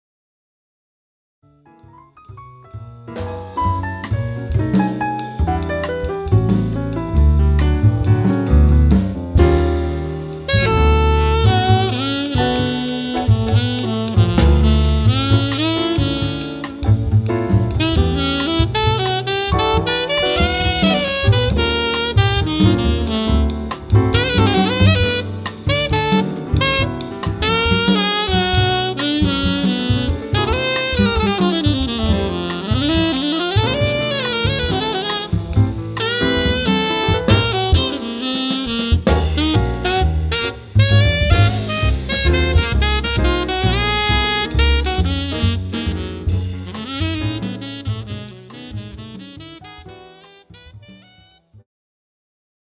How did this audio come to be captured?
Recorded at Clowns Pocket Studio, London 2014